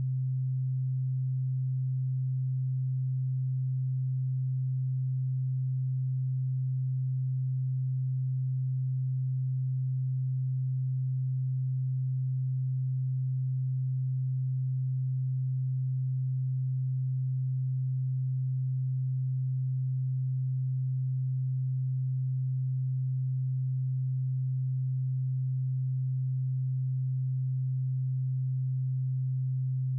130Hz_-27.dB.wav